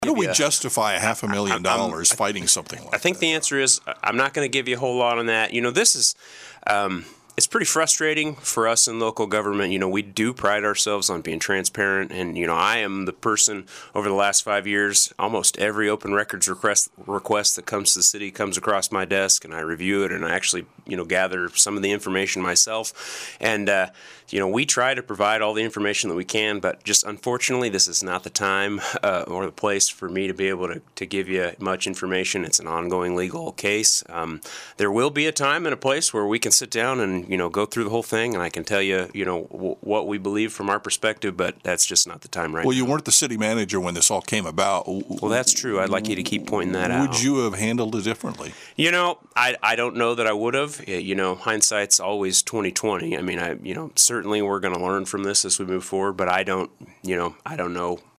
Interim City Manager Jacob Wood joined in the KSAL Morning News Extra with a look at issues around Salina including the litigation that remains in motion.